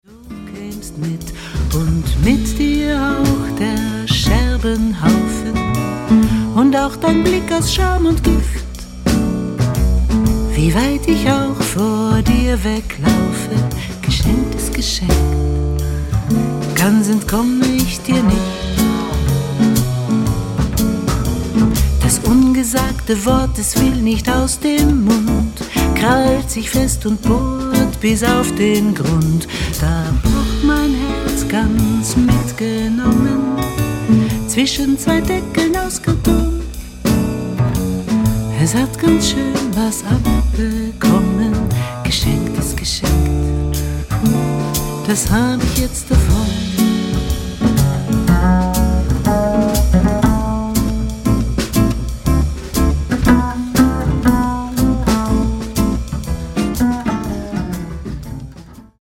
Gitarre, Bass, Ukulele, Gesang
Akkordeon
Klavier, Saxophone, Flöten